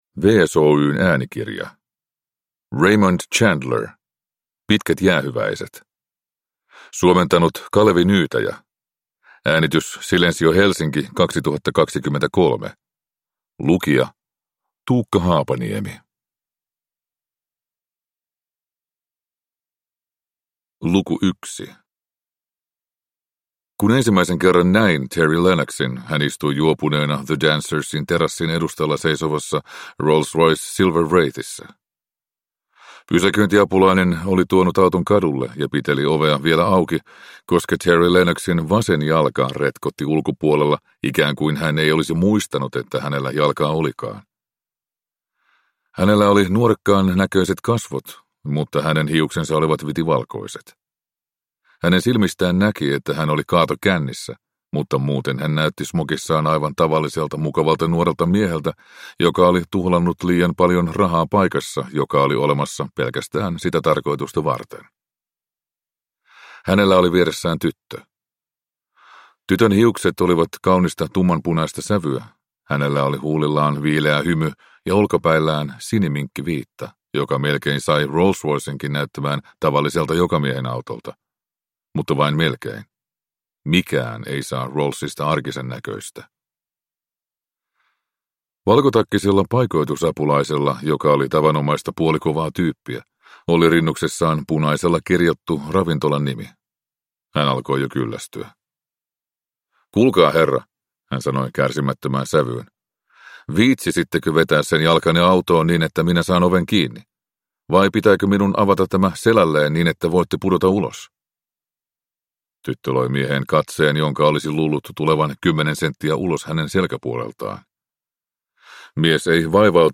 Pitkät jäähyväiset – Ljudbok